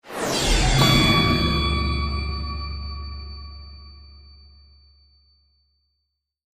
SFX嗖的一声炫酷音效下载
SFX音效